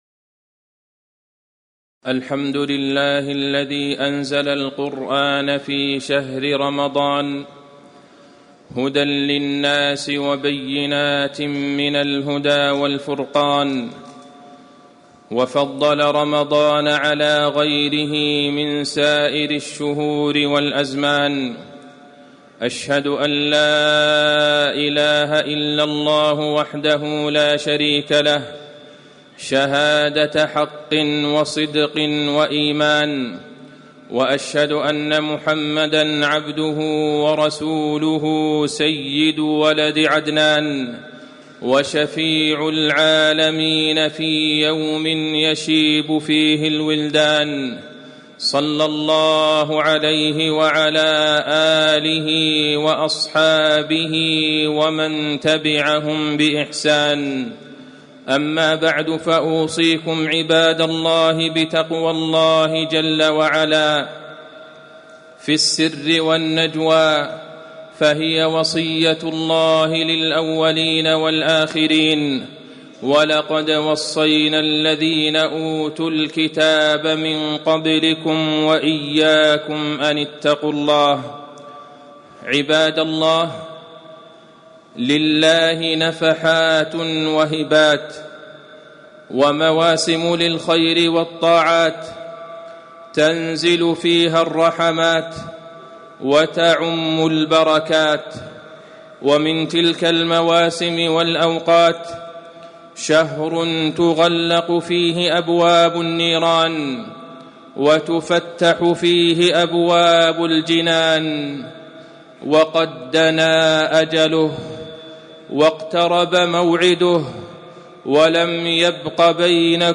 تاريخ النشر ٢٣ شعبان ١٤٣٨ هـ المكان: المسجد النبوي الشيخ: فضيلة الشيخ د. عبدالله بن عبدالرحمن البعيجان فضيلة الشيخ د. عبدالله بن عبدالرحمن البعيجان التأهب والاستعداد لشهر رمضان